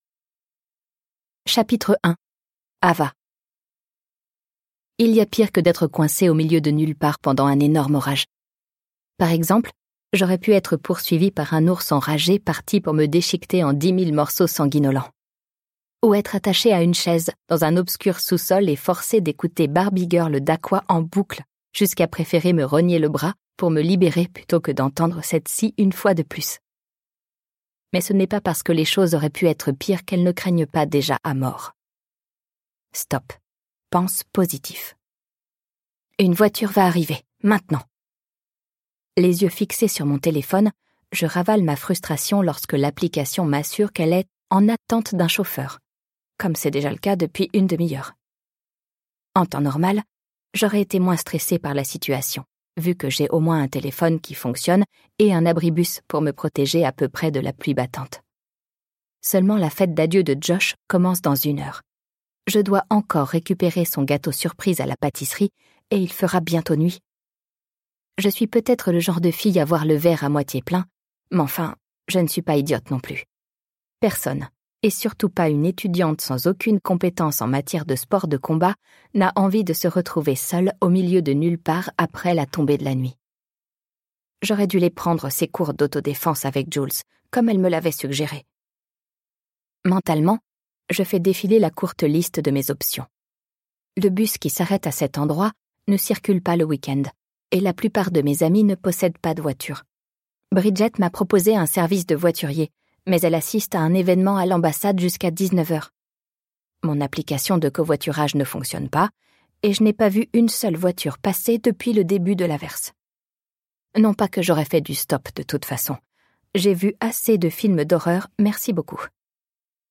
Click for an excerpt - Twisted : Twisted Love - Tome 01 de Ana Huang